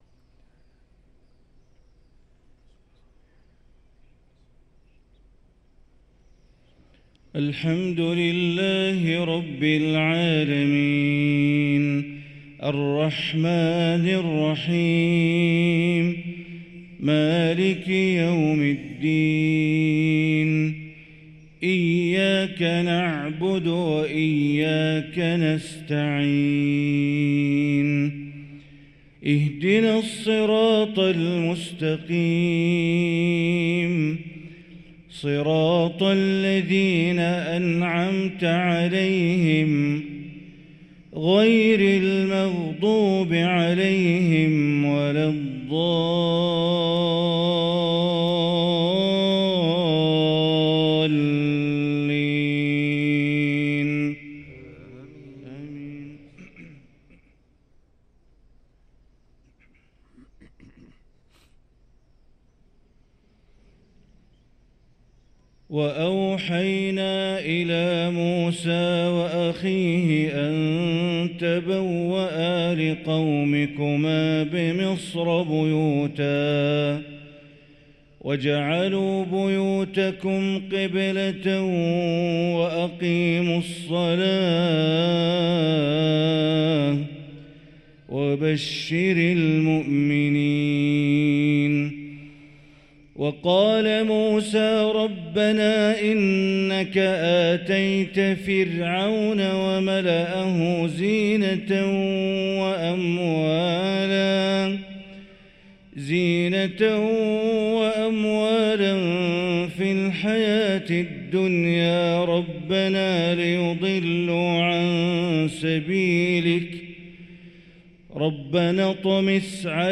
صلاة الفجر للقارئ بندر بليلة 12 جمادي الأول 1445 هـ
تِلَاوَات الْحَرَمَيْن .